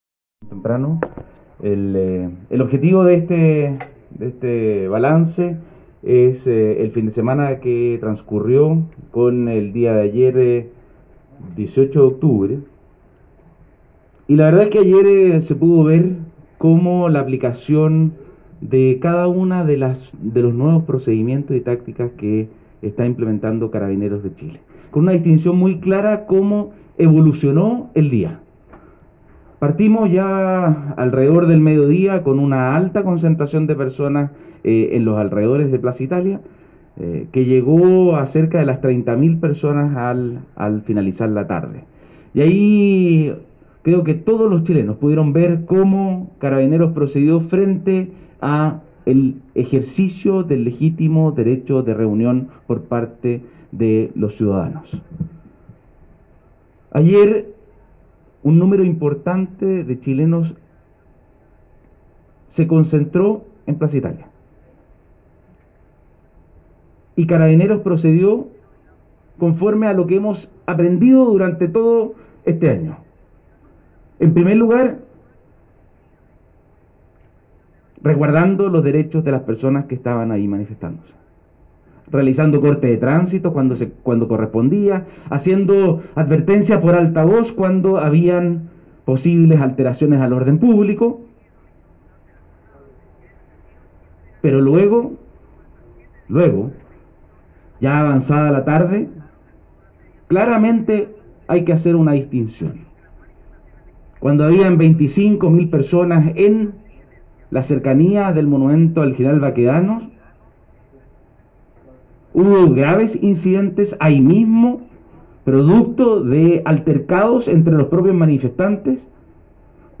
Escuchemos extracto del punto de prensa de hoy convocado por Carabineros y representantes del Ministerio del Interior, General Inspector, Ricardo Yáñez, Director Nacional Orden y Seguridad y el Subsecretario del Interior, Juan Francisco Galli
General-Ricardo-Yañez.mp3